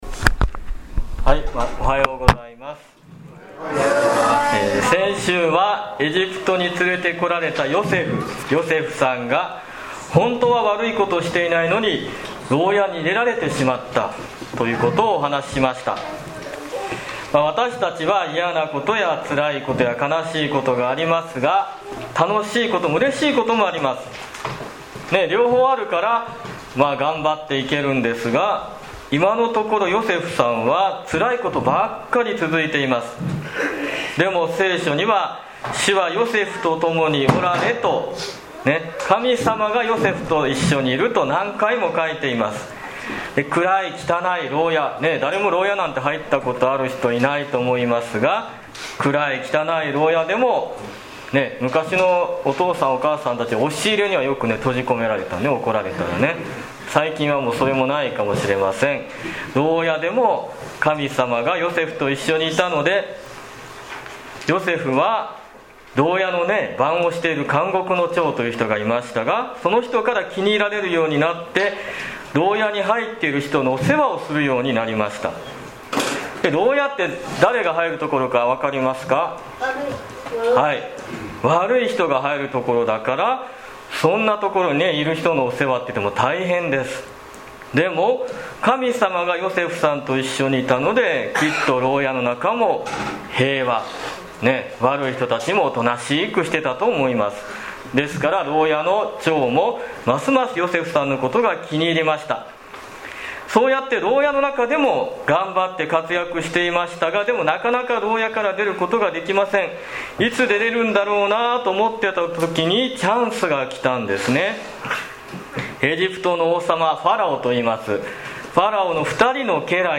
2018年4月30日礼拝メッセージ – 香川県坂出市のキリスト教会 白金キリスト教会
本日の礼拝は「子どもと一緒礼拝」でした。